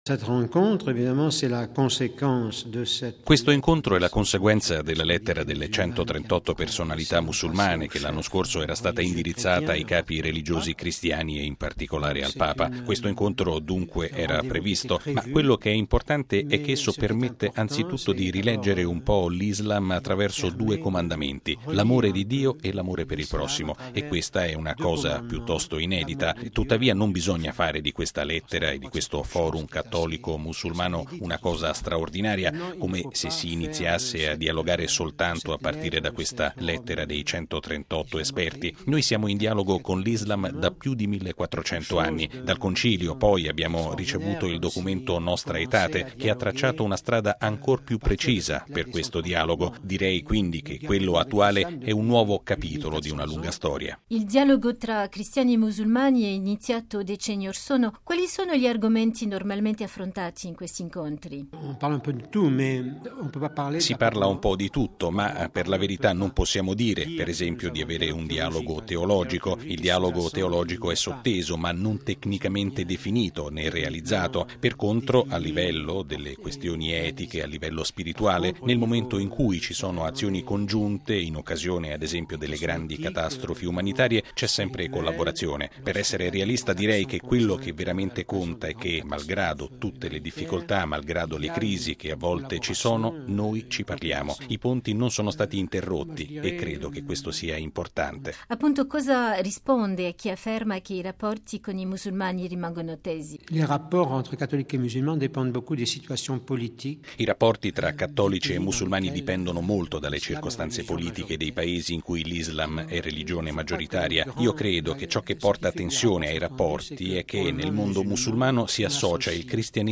Intervista con il cardinale Tauran